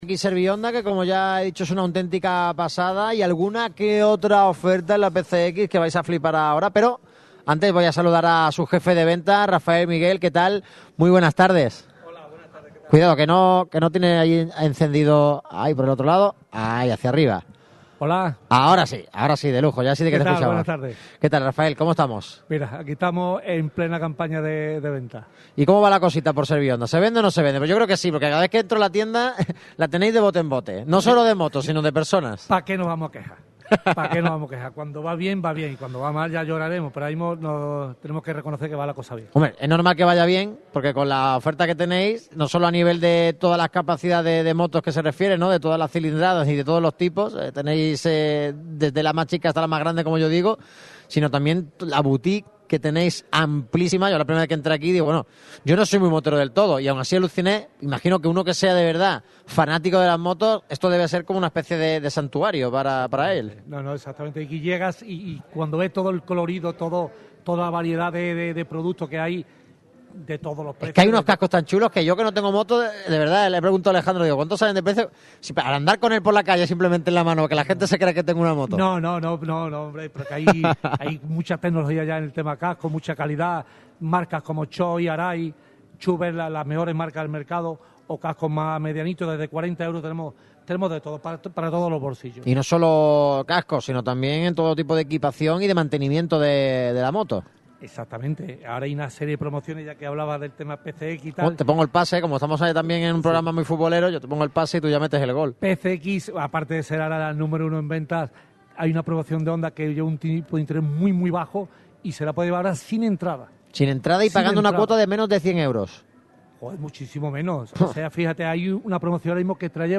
Radio Marca Málaga se desplazó hasta las instalaciones de ServiHonda, el Concesionario Oficial Honda Motos para Málaga y provincia, para realizar su habitual programa. En este se trató lo último del deporte malagueño y se habló de los numerosos servicios que da el concesionario a sus clientes.